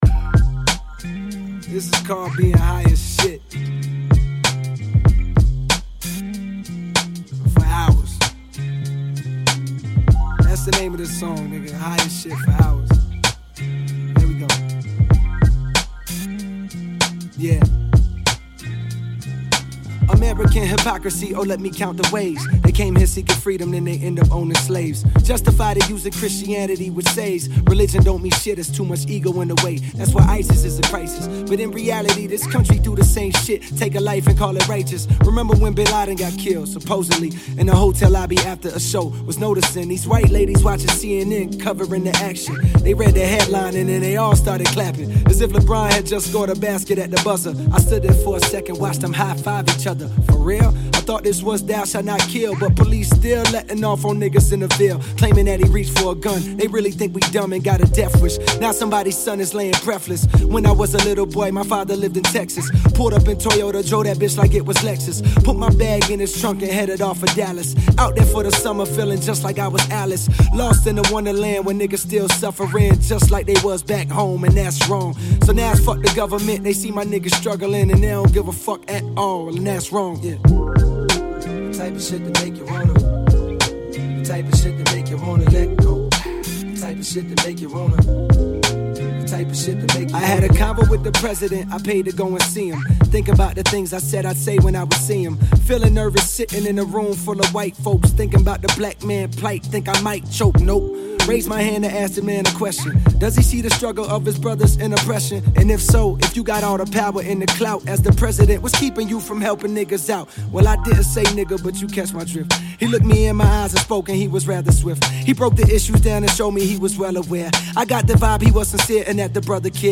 HipHop/Rnb
Here is a new Hip-hop single he has released.